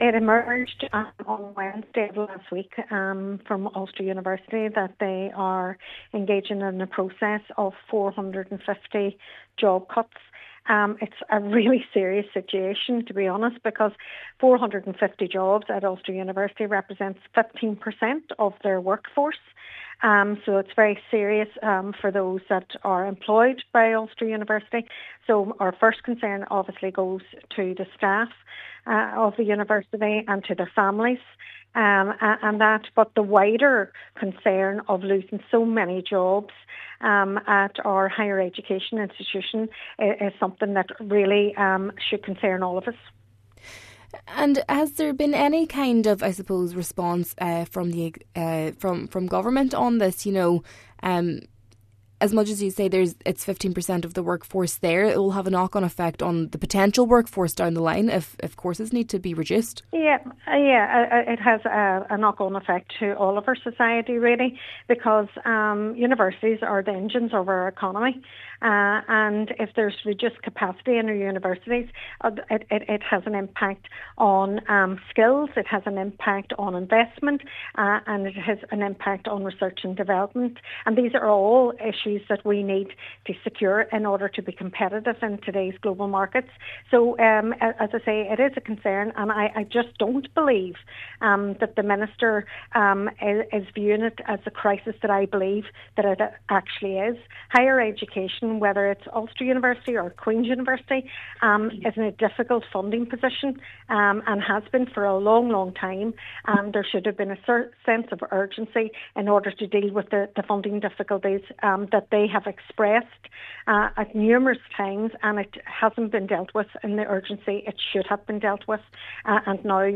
The Economy Spokesperson for the SDLP says she doesn’t understand how the job cuts align with the planned expansion of an extra 10,000 students at the Magee Campus in Derry: